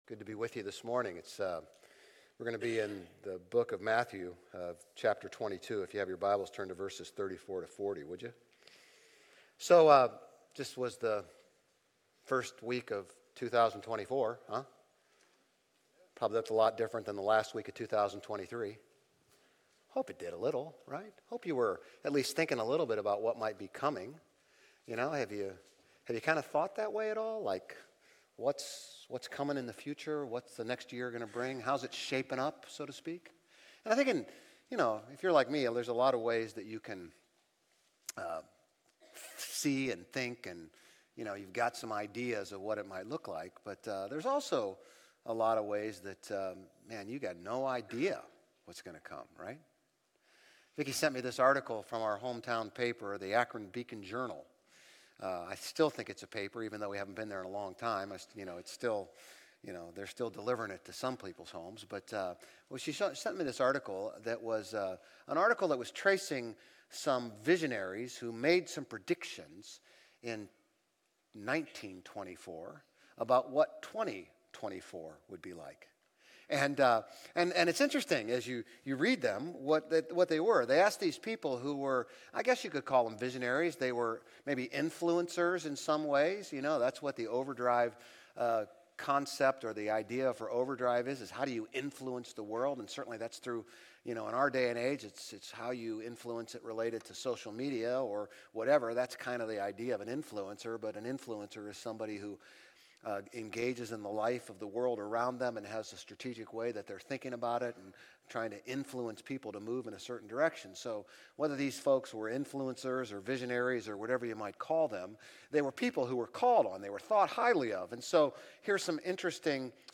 Grace Community Church Old Jacksonville Campus Sermons Missional Alignment on Relational Evangelism Jan 08 2024 | 00:35:42 1x 00:00 / 00:35:42 Subscribe Share RSS Feed Share Link Embed